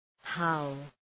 Προφορά
{haʋ}